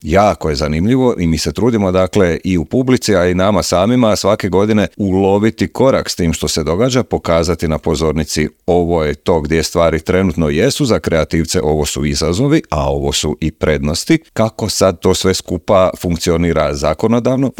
Tim povodom u intervjuu Media servisa